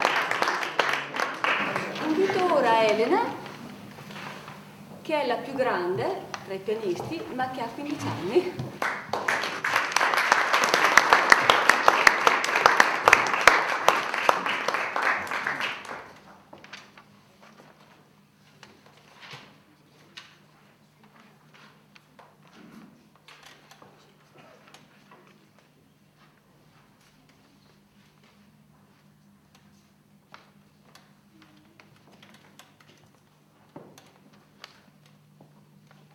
I “Mozart Boys&Girls” in concerto - Sabato 21 febbraio 2009